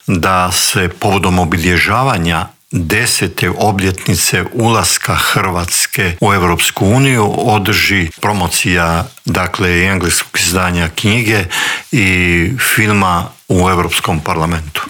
ZAGREB - Povodom drugog izdanja knjige ‘Diplomatska oluja - sjećanja najdugovječnijeg Tuđmanovog ministra‘, u Intervjuu tjedna Media servisa gostovao je bivši ministar vanjskih poslova i posebni savjetnik premijera Mate Granić.